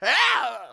binf_fall.wav